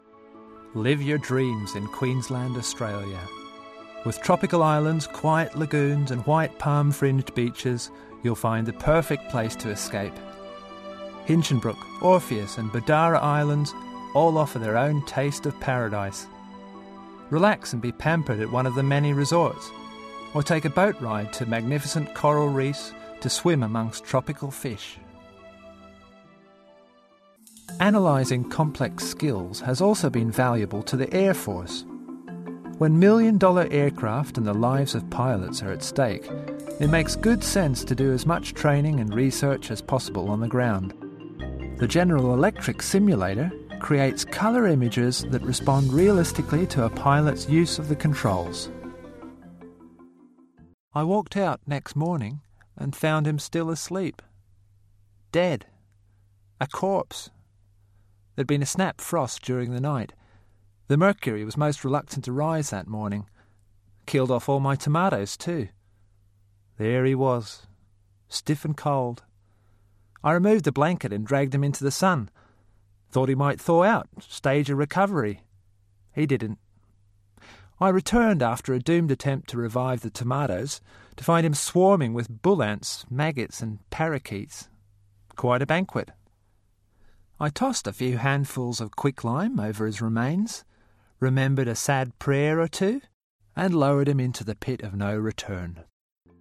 20s-40s. Male. Australian.